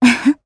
Xerah-Vox_Happy2_jp.wav